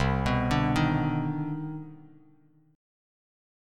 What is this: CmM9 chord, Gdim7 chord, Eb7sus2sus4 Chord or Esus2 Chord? CmM9 chord